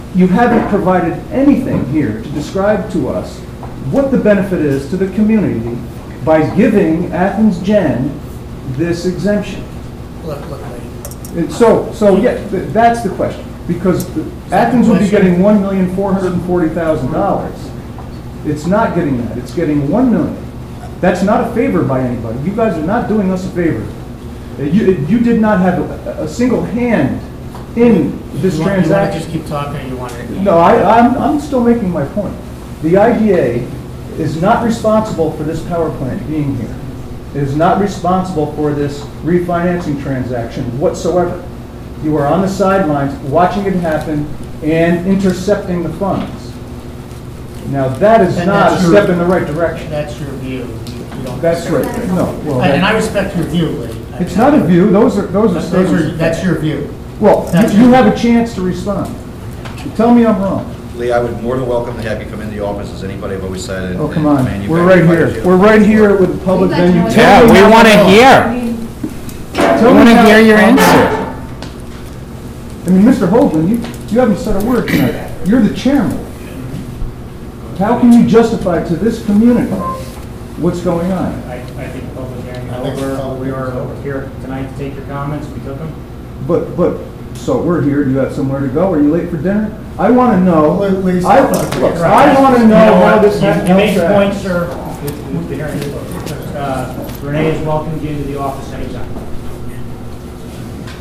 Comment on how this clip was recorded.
This conversation took place after public hearing had technically closed.